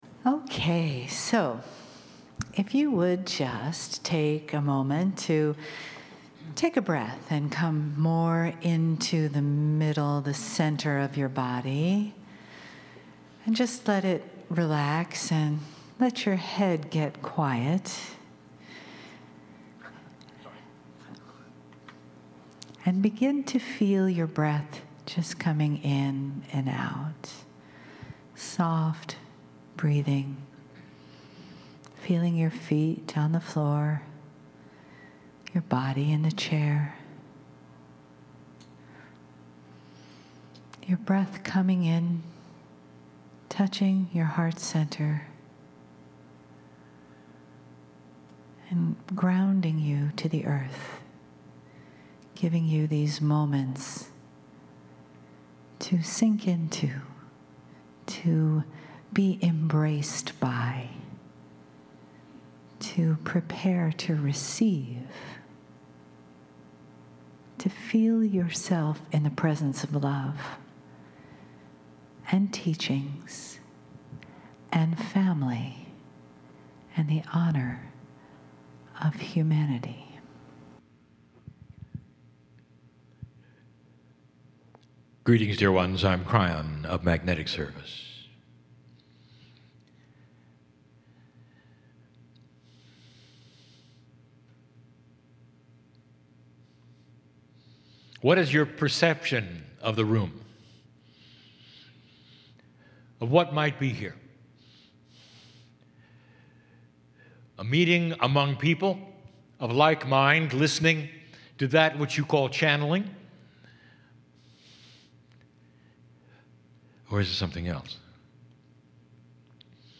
Minneapolis, Minnesota
KRYON CHANNELLING